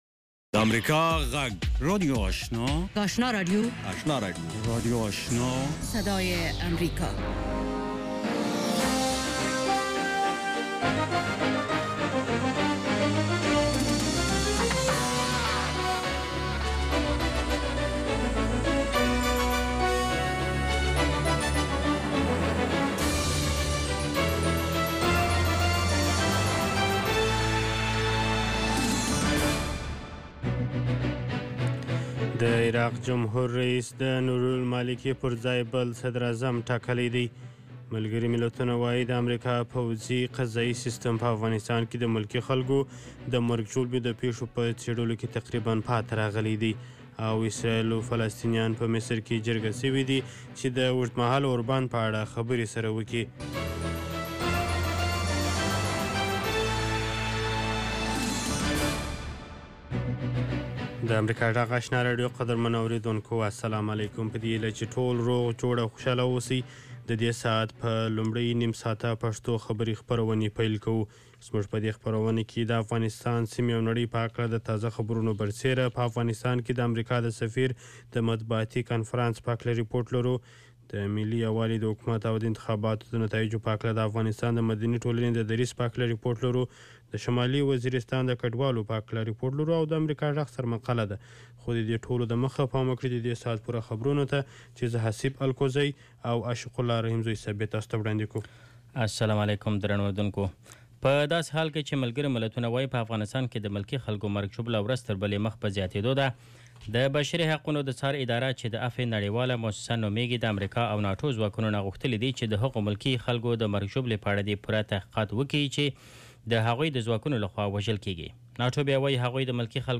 لومړنۍ سهارنۍ خبري خپرونه